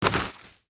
Light  Dirt footsteps 2.wav